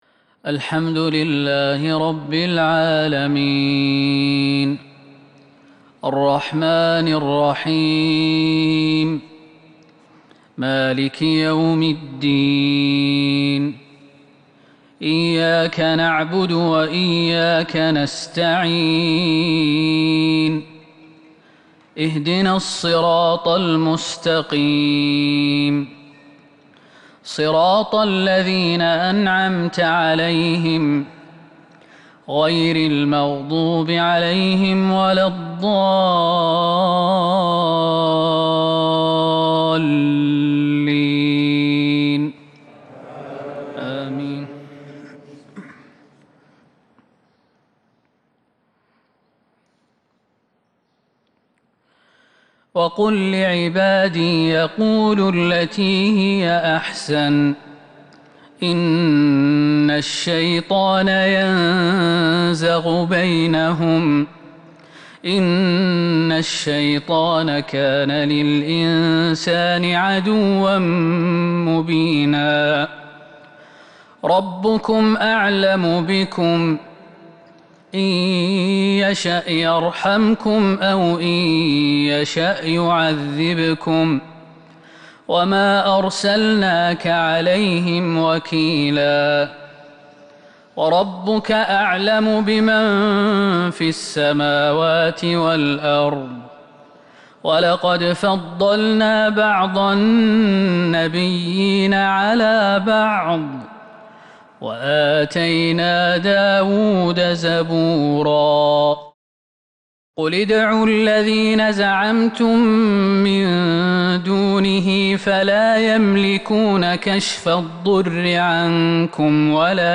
مغرب الأحد 7-4-1442هـ من سورة الإسراء  maghrib prayer from surah Al-Isra 22/11/2020 > 1442 🕌 > الفروض - تلاوات الحرمين